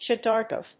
アクセント・音節shádow・gràph